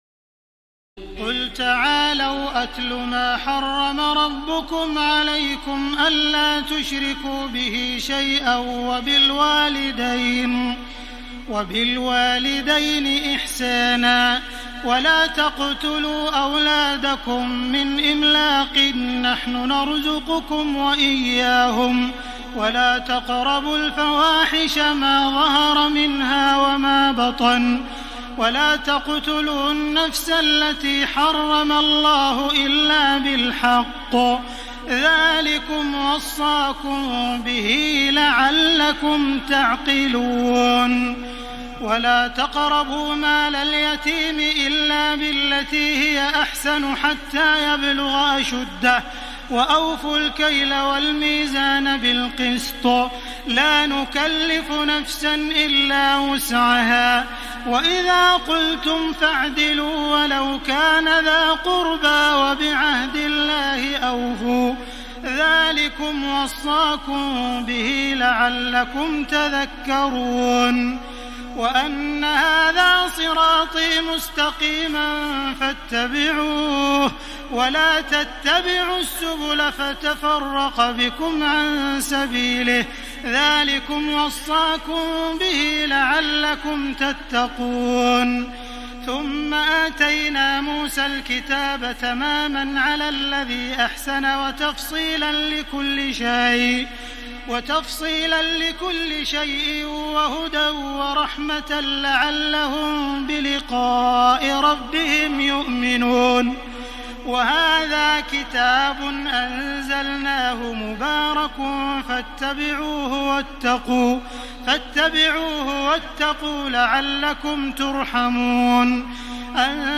تراويح الليلة السابعة رمضان 1433هـ من سورتي الأنعام (151-165) و الأعراف (1-64) Taraweeh 7 st night Ramadan 1433H from Surah Al-An’aam and Al-A’raf > تراويح الحرم المكي عام 1433 🕋 > التراويح - تلاوات الحرمين